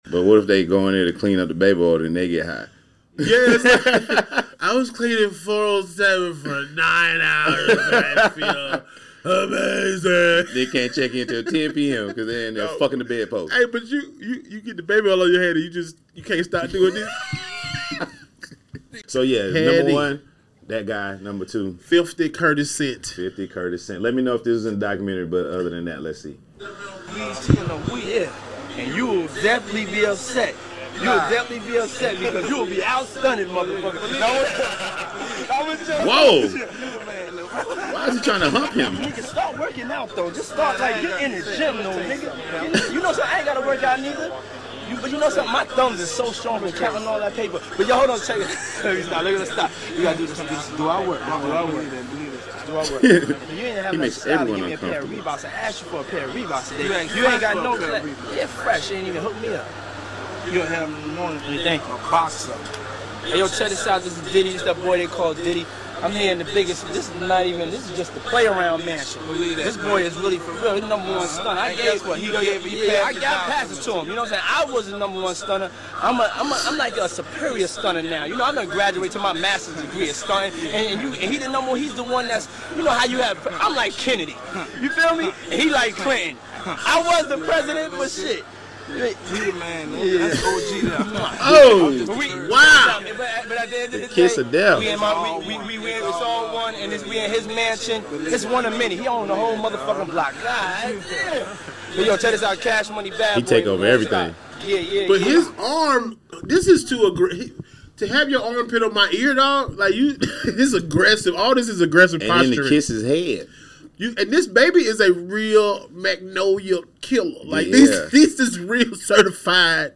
Expect loud laughs, savage roasts, and unpredictable moments — all in under 60 seconds.
High-energy comedy from two seasoned hosts Honest takes, unexpected laughs, and spontaneous jokes